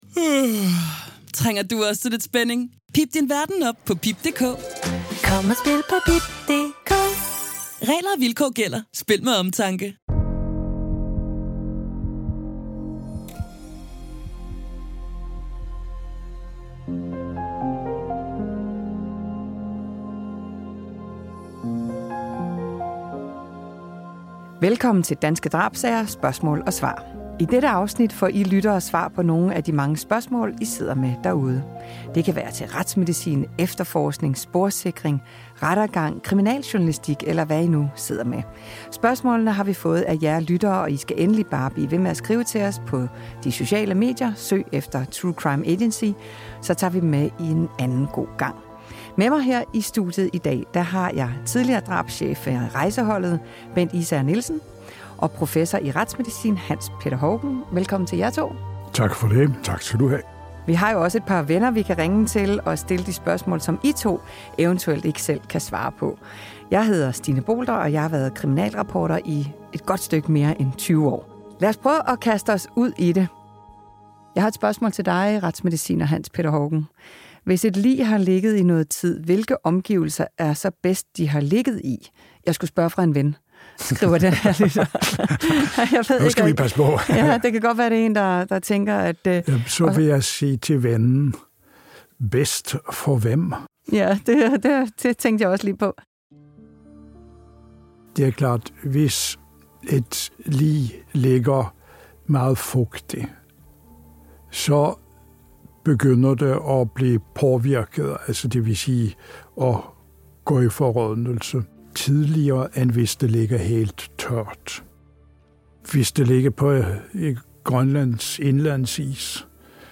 Det kan være om retsmedicin, kriminalteknik, efterforskning, rettergang, kriminaljournalistik og meget andet. De medvirkende trækker tråde til sager, de selv har stået med og øser ud af deres store viden og indsigt.